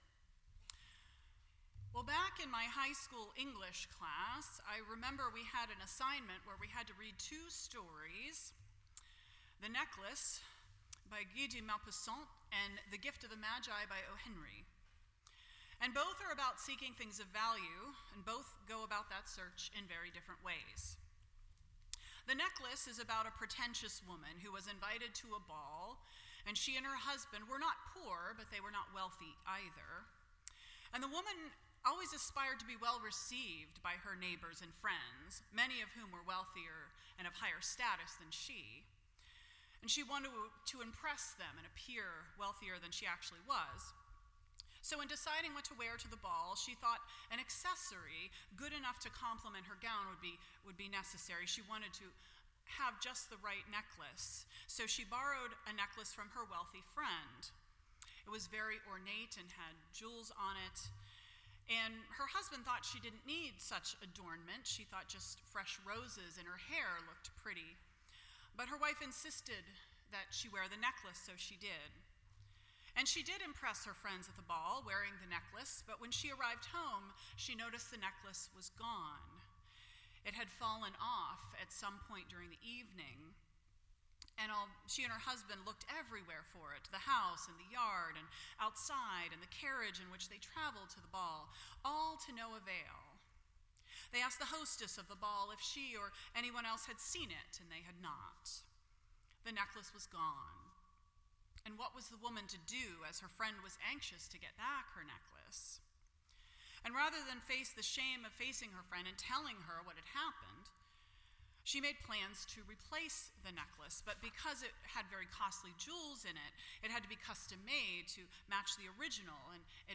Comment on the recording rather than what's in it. None Service Type: Epiphany Sunday %todo_render% Share This Story